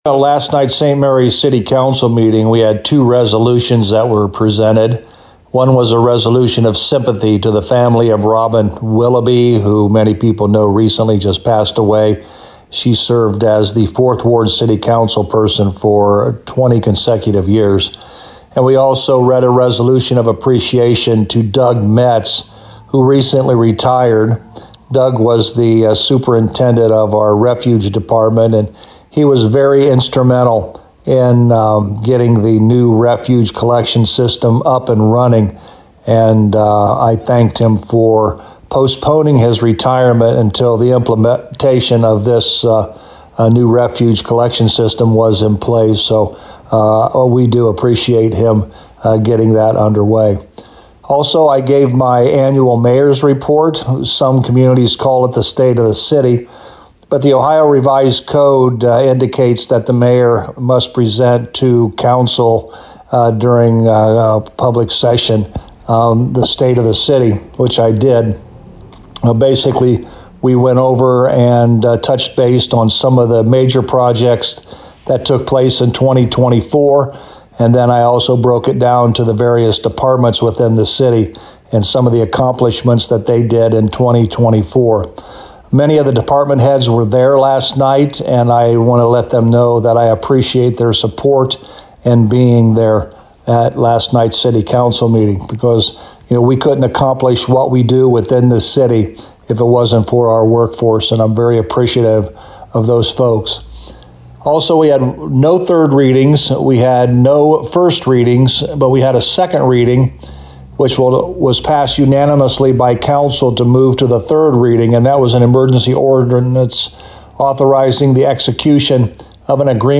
To hear St Marys Mayor Joe Hurlburt's summary of the March 10th City Council Meeting: